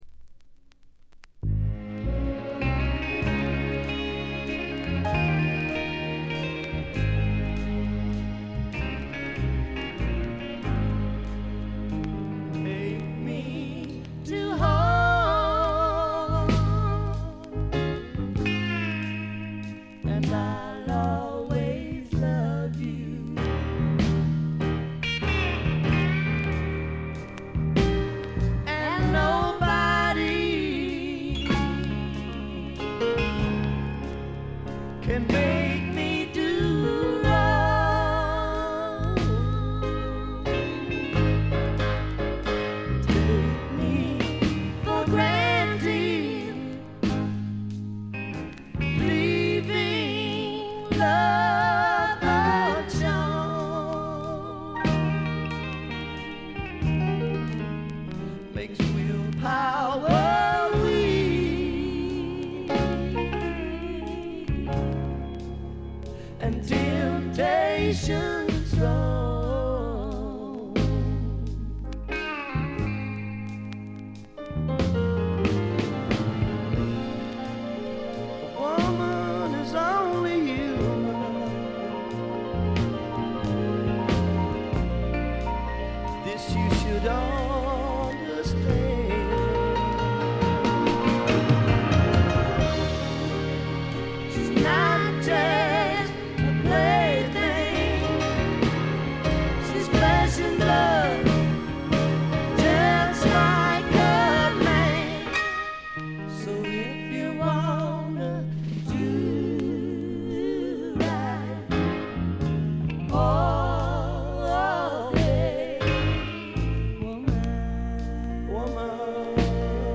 ところどころで軽微なチリプチ。
まさしくスワンプロックの原点ともいうべき基本中の基本盤。
試聴曲は現品からの取り込み音源です。